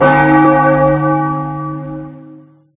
Bell1.ogg